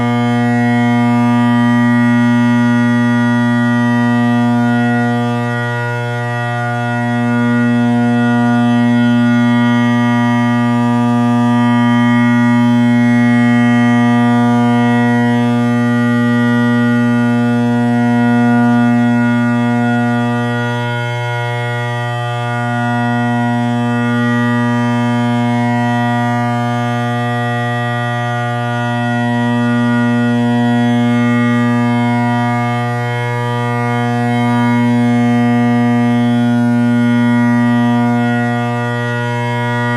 Drone Sounds of the GHB
Below are mp3 recordings of great highland bagpipe drones made by various makers coupled with many of the modern drone reeds that are commercially available.
Canning (carbon bass)